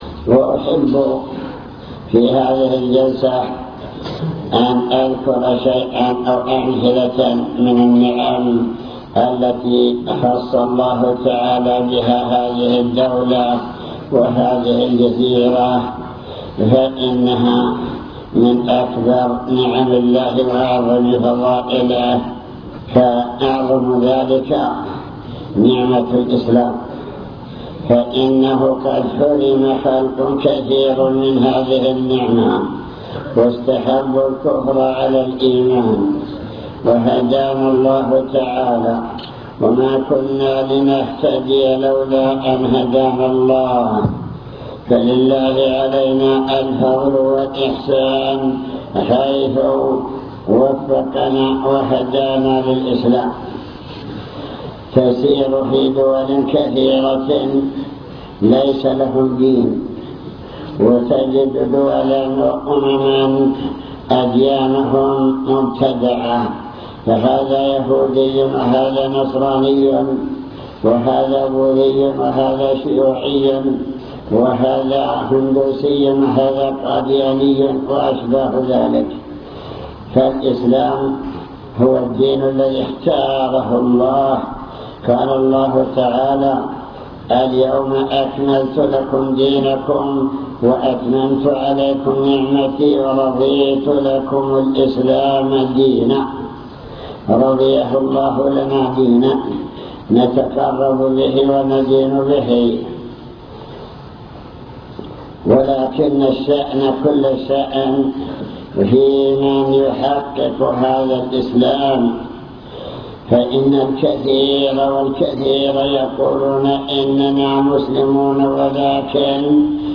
المكتبة الصوتية  تسجيلات - محاضرات ودروس  محاضرة بعنوان شكر النعم (3) نماذج من نعم الله تعالى التي خص بها أهل الجزيرة